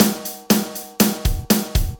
Although they do not sound bad, they are slightly 'weak' sounding and could do with a bit more body and punch.
After being distorted the signal is fed into a compressor with an attack of around 15ms and a release of about 130ms - i tweaked the ratio and threshold until I got a sound I liked - this sound ended up being the result of 10dB(!!!!) of compression on peaks but this really emphasized the ring of the snare and gave that very fat and warm indie sound I was trying to achieve but with some pumping.